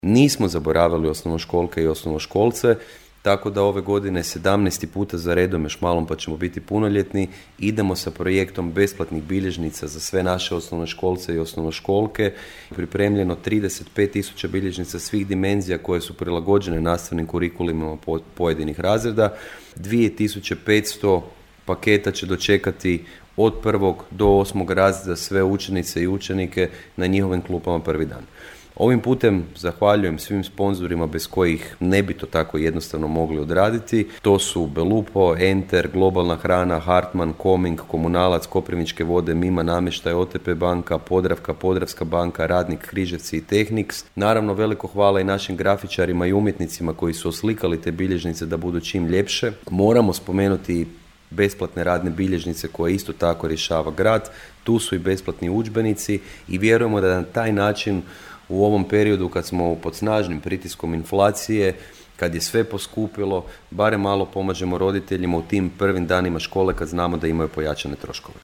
Dobre želje uoči prvog dana nastave uputio je gradonačelnik Mišel Jakšić, a ranije komentirao za Podravski radio;